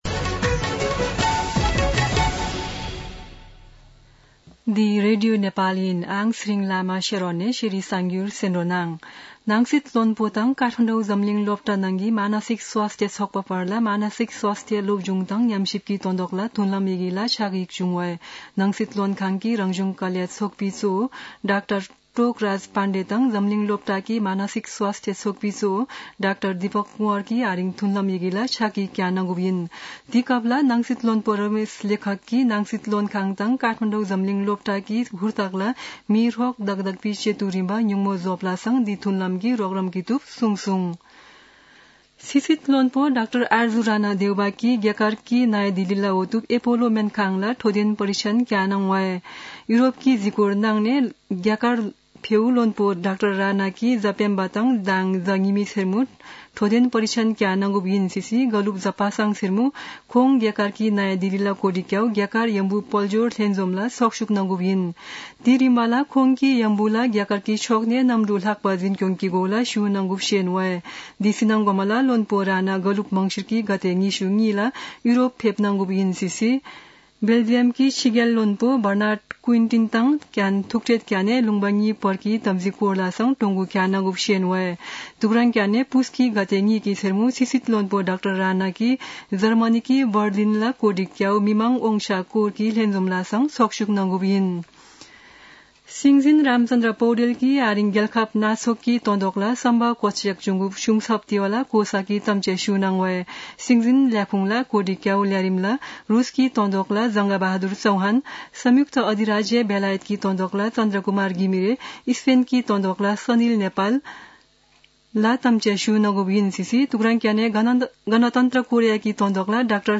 शेर्पा भाषाको समाचार : ९ पुष , २०८१
Sherpa-News-2.mp3